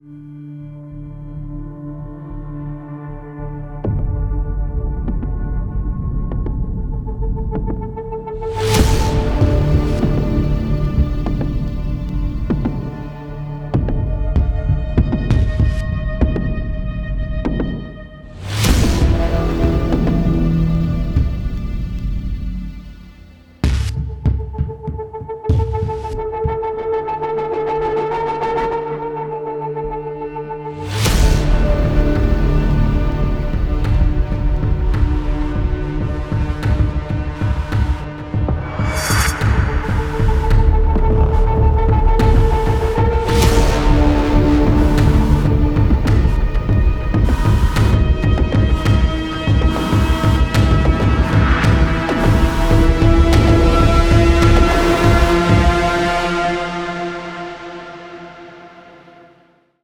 暗黑质感、深沉音色
ASHLIGHT 以单色的音景、脉动的质感、复杂的主奏和冰冷的氛围构成了粒子合成器的第三部曲。 从引人注目的扫频，到引人深思的键盘、震撼世界的音墙。
乐器类型: 影视式质感实时乐器
声音类型: 氛围, Pad, 主奏（Lead）, 过渡, 键盘, 效果器, 超低音和脉冲